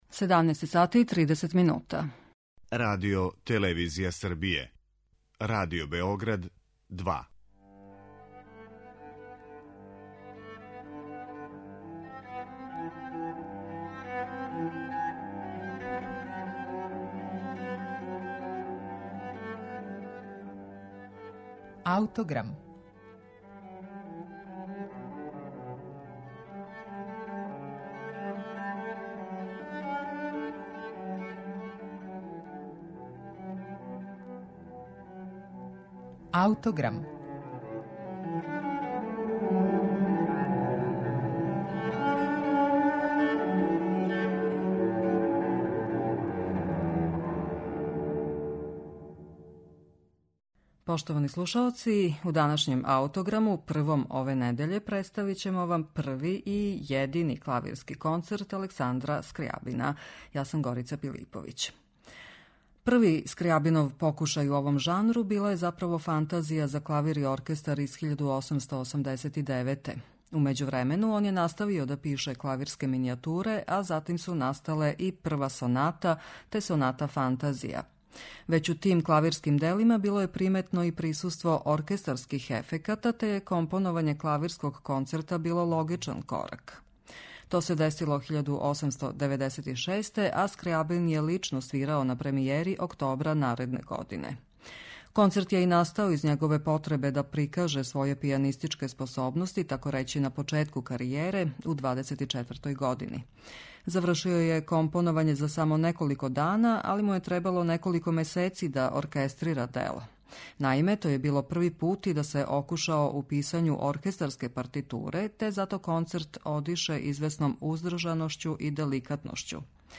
Скрјабинов први и једини концерт за клавир и оркестар